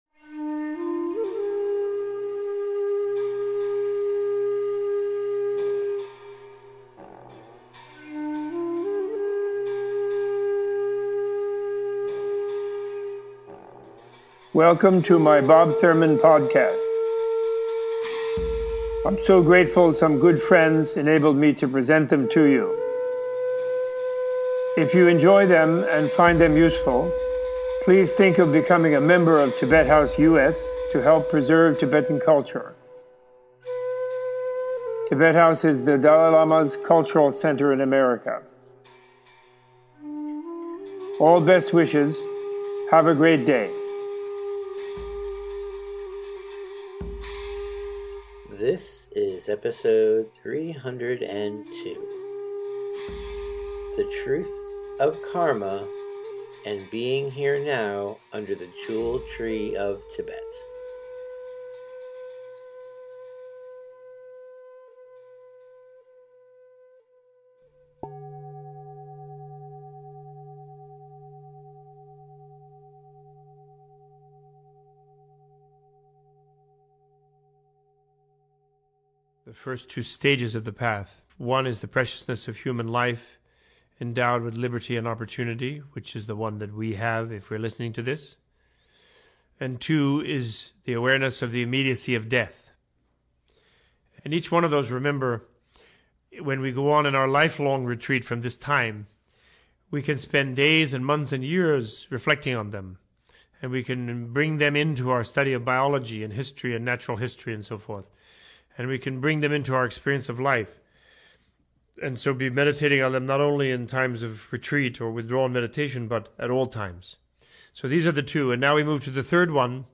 Opening with a short introduction to the first two stages of the Lam Rim (Path to Enlightenment), the preciousness of human life endowed with liberty and opportunity, and awareness of the immediacy of death, Robert Thurman gives a teaching on the inexorability of the cause and effect of evolutionary action. This episode includes a recommendation of “Be Here Now” by Ram Dass and an in-depth exploration of non-duality, karma, causation and the Buddha’s evolutionary theory of biology.